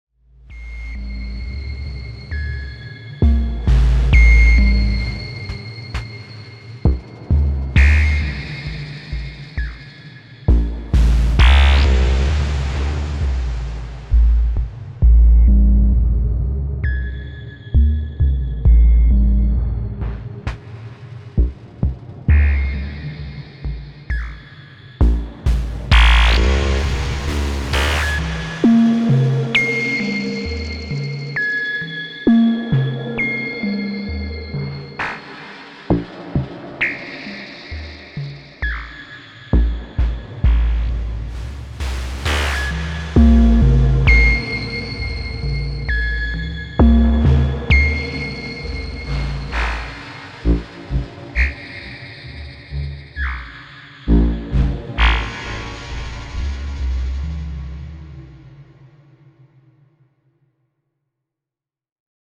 Just added Shakmat Griffin’s Claw to the setup.
A polite one, where it modulates the DFAM only + a few knob turning on the DFAM and Fors Ego/Romb thrown in the mix